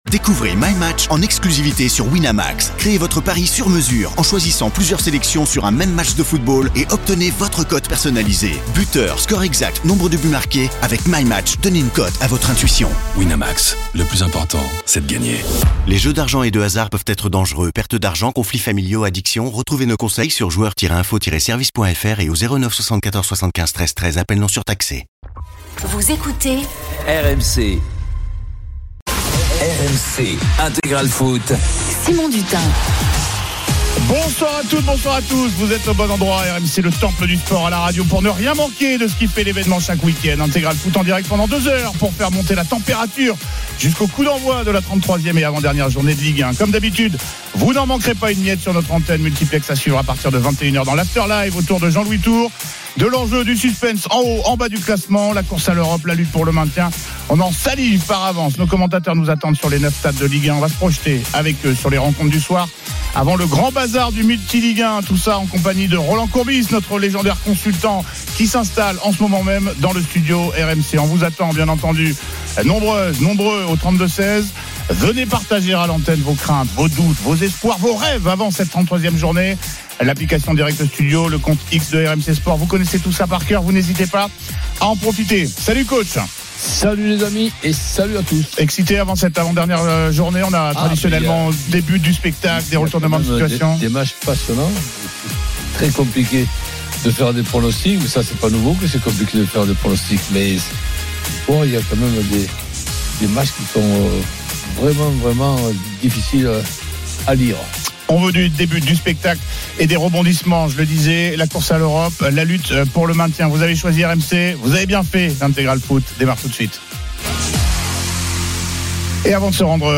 Le rendez vous Ligue 2 de RMC. Huit matches par journée à suivre en direct et des acteurs du championnat (joueurs, entraîneurs, présidents) invités pendant deux heures.
RMC est une radio généraliste, essentiellement axée sur l'actualité et sur l'interactivité avec les auditeurs, dans un format 100% parlé, inédit en France.